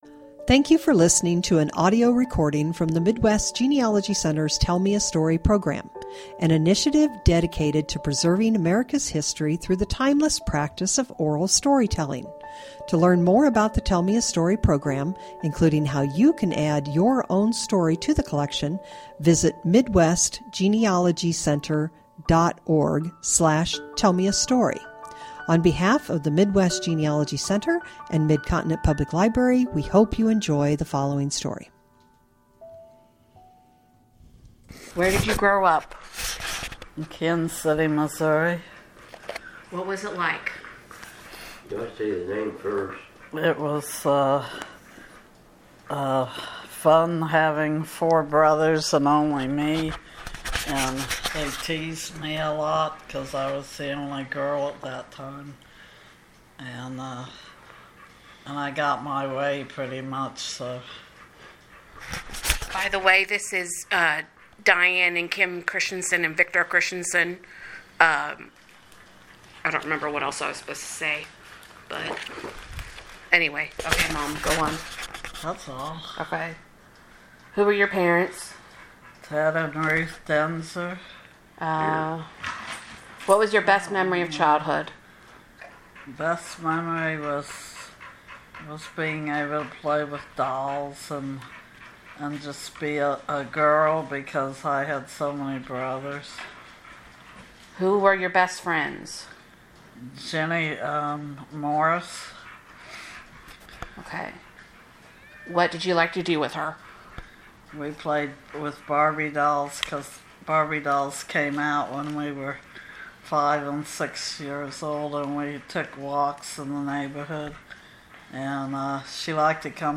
Oral History
Stereo